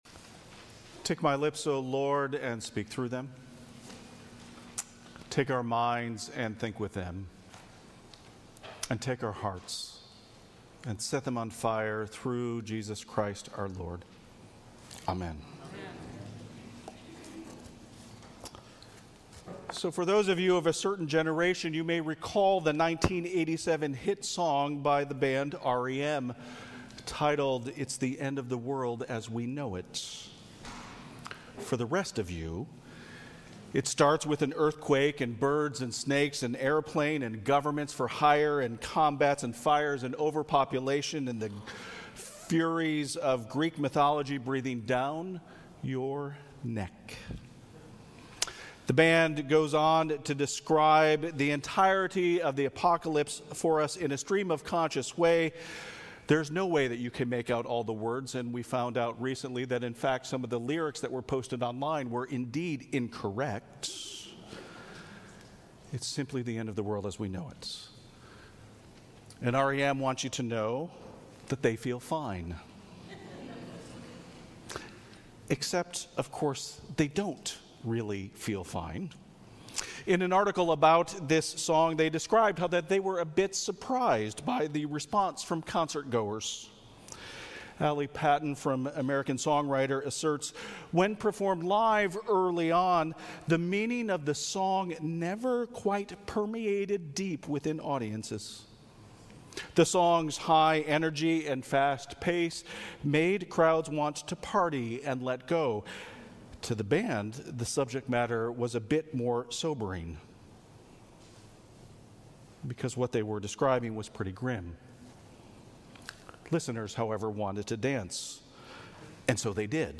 Sermons | Grace Episcopal Church
Preacher: The Rt. Rev. Phil LaBelle, Bishop of the Diocese of Olympia image: Festival of Lights, John August Swanson